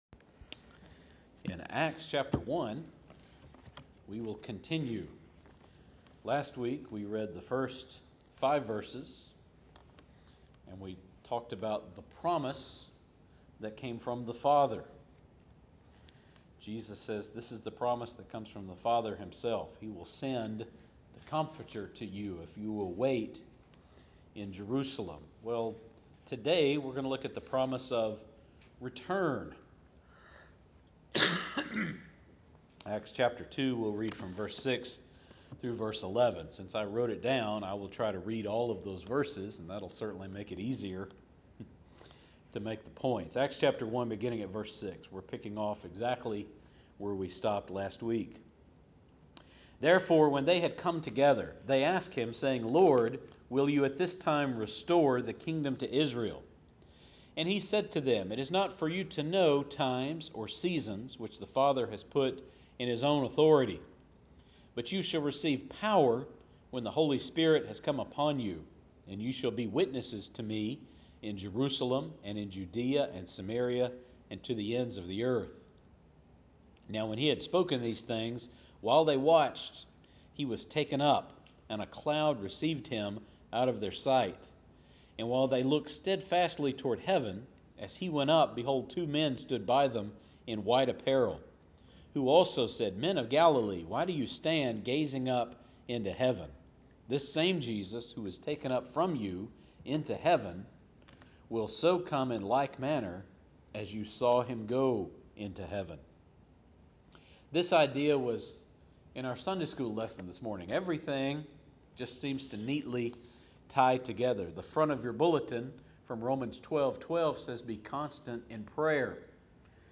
Acts 1 Sermon Series